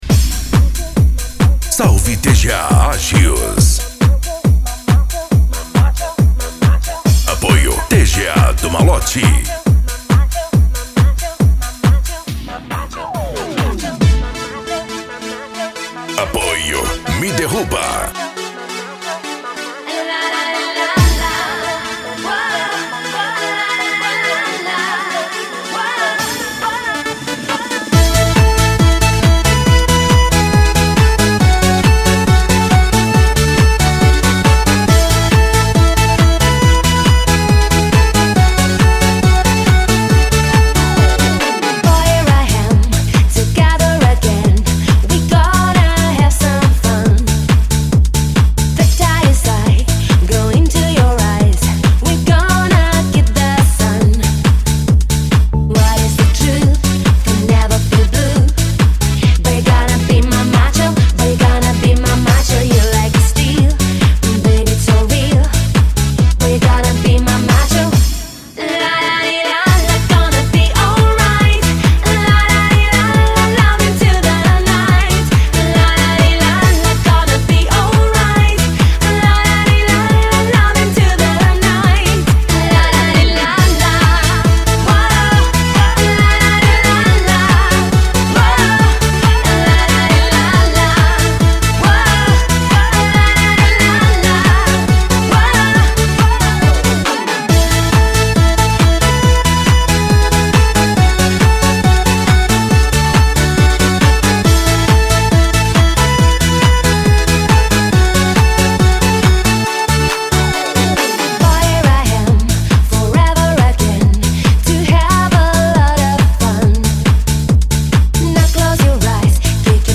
Euro Dance
Funk
Sets Mixados